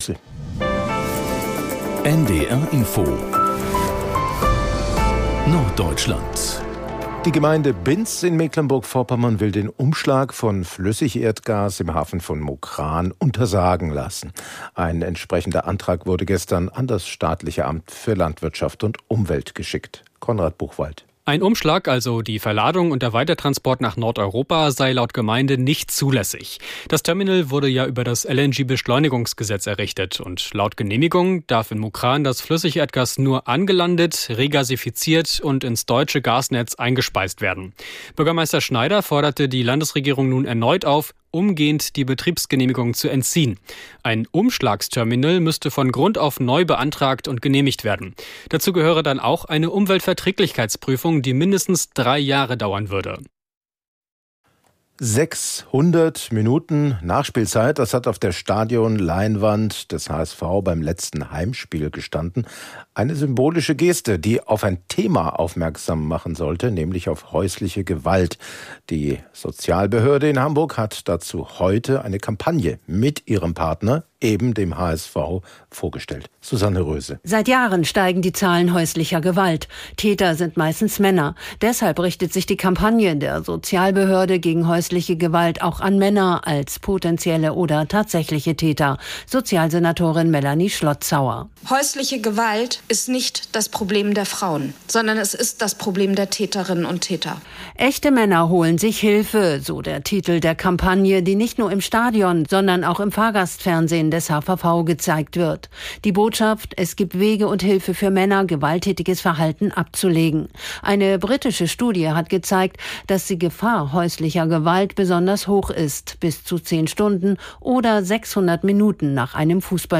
… continue reading 533 에피소드 # Nachrichten # NDR Info # Tägliche Nachrichten